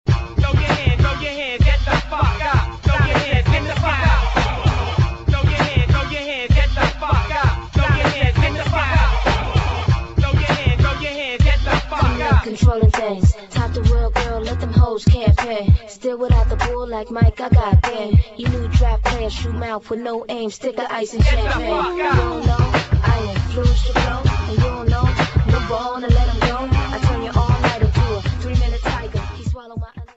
[ R&B | HIP HOP ]